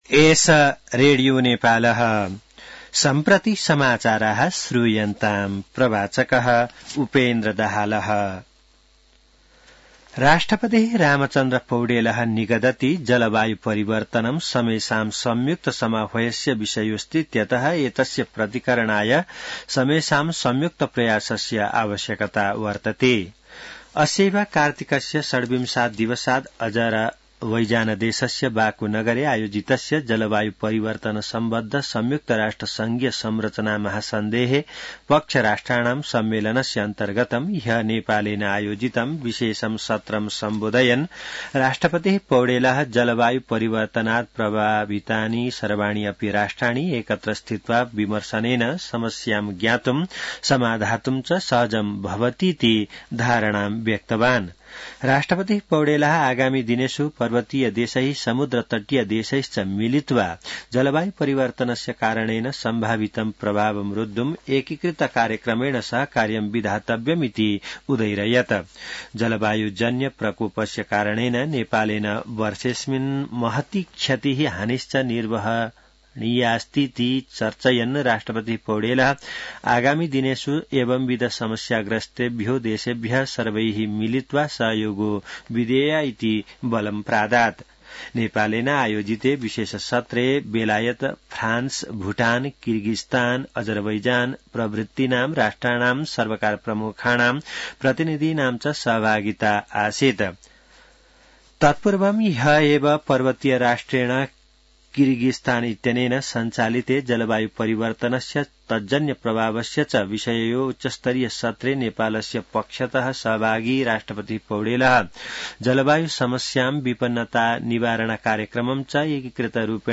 An online outlet of Nepal's national radio broadcaster
संस्कृत समाचार : ३० कार्तिक , २०८१